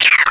painm.wav